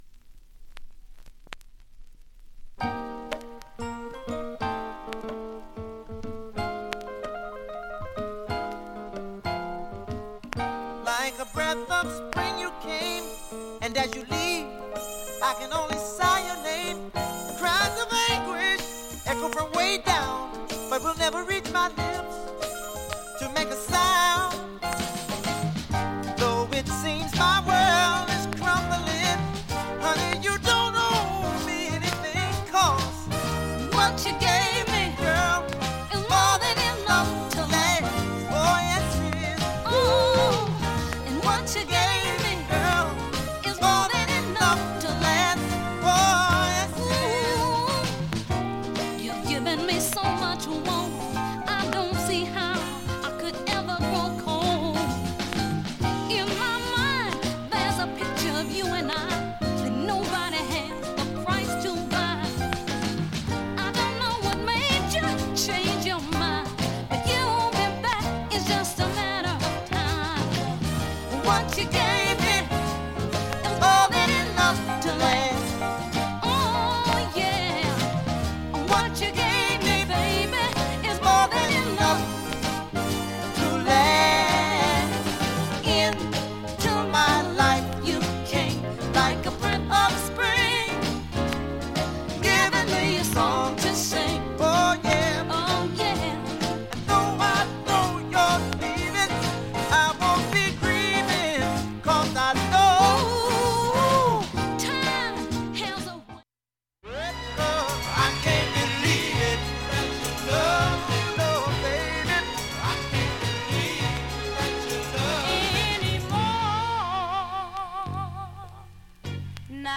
音質良好全曲試聴済み。
3,(4m52s〜)B-4終りかすか3回と1回プツ
３回までのかすかなプツ３箇所
単発のかすかなプツが６箇所